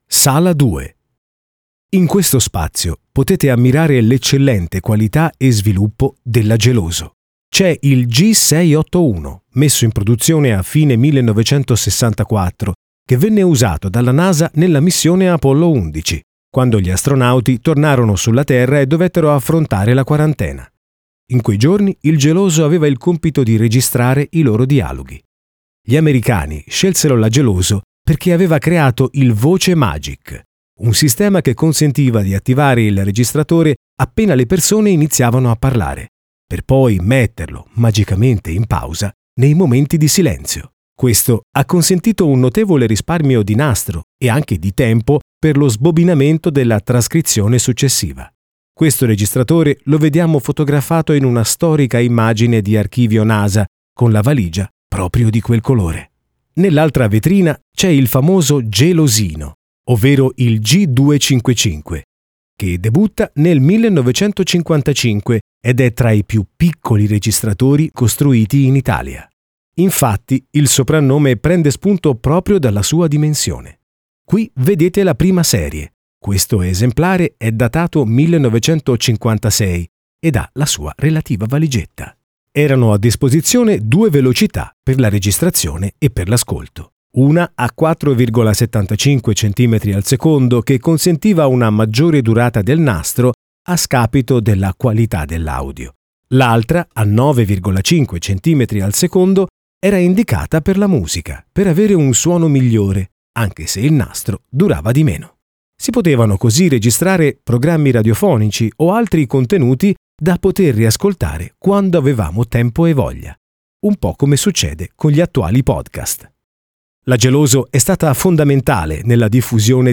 Ascolta l’audioguida della mostra al MMAB di Montelupo Fiorentino.